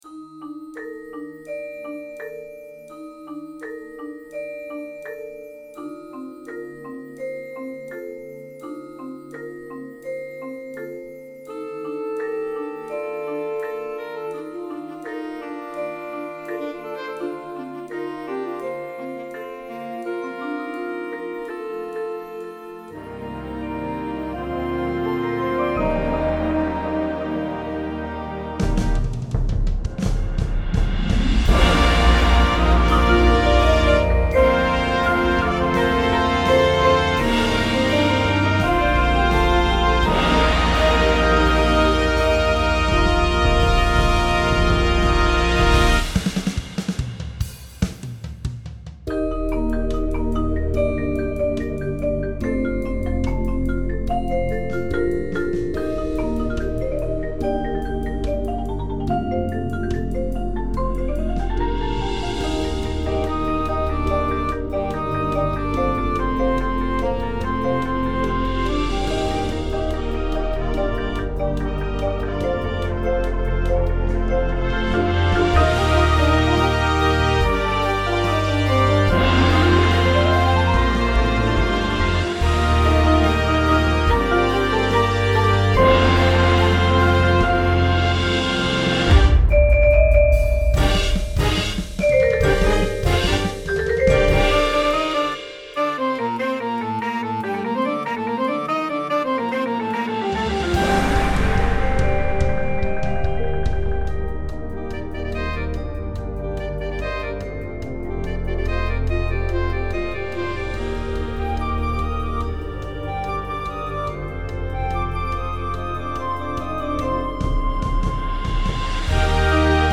• Flute
• Trumpet 1, 2
• Tuba
• Snare Drum
• Marimba – Two parts
• Vibraphone – Two parts